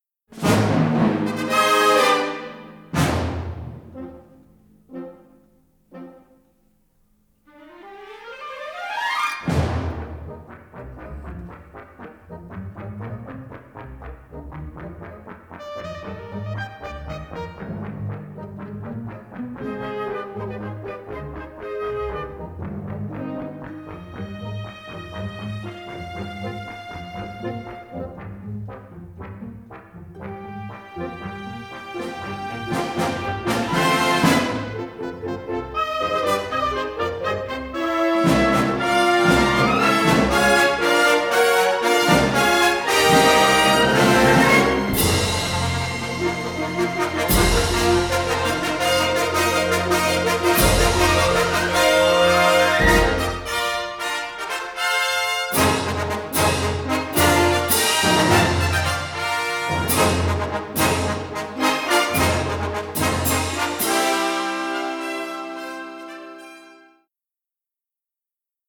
Unusually crisp, punchy recording